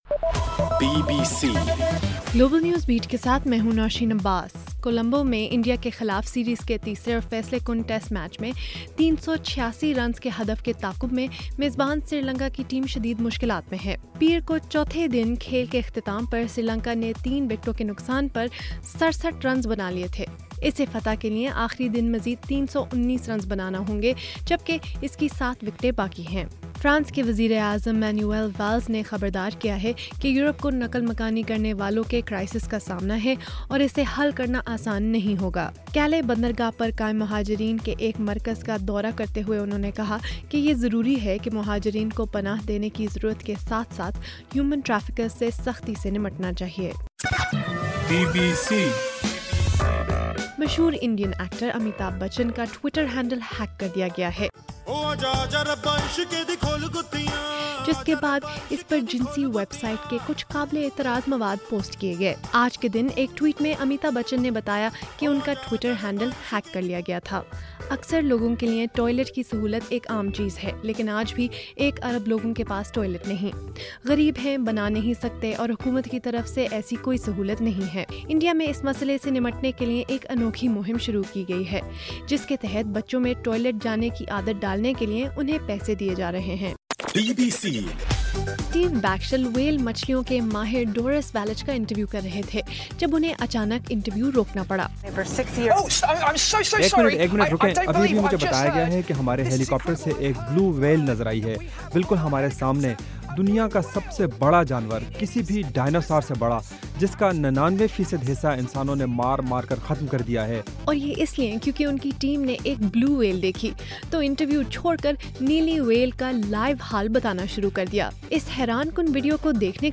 اگست 31: رات 9 بجے کا گلوبل نیوز بیٹ بُلیٹن